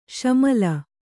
♪ śamala